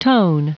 Prononciation du mot tone en anglais (fichier audio)
Prononciation du mot : tone